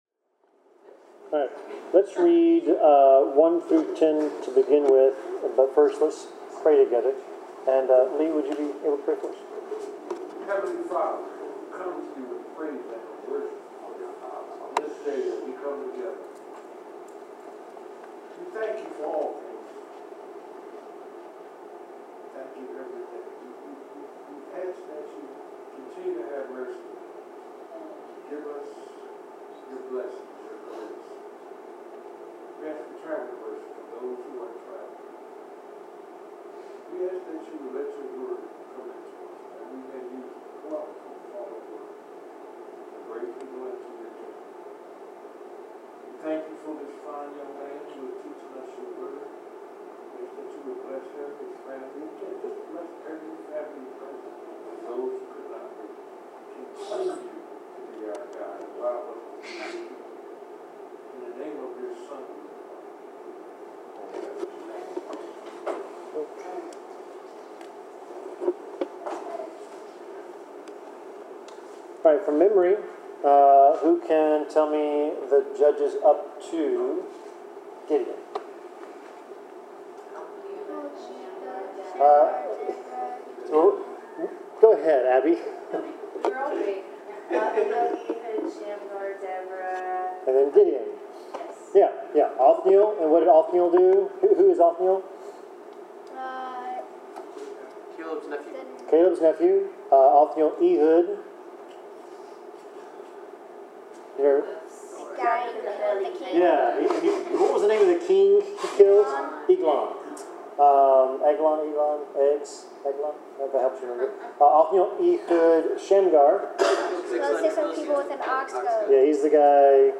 Bible class: Judges 6
Service Type: Bible Class